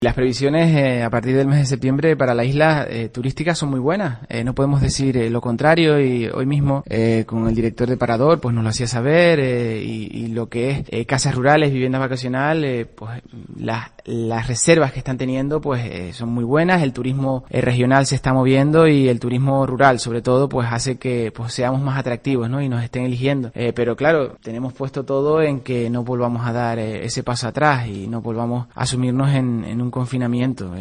Mariano Hernández Zapata, presidente del Cabildo de la isla, ha asegurado en La Mañana de COPE en Canarias, en el especial “8 días 8 islas” que comenzaba este martes en El Parador de La Palma que “los hoteles y los sectores relacionados con el turismo están sufriendo este parón”.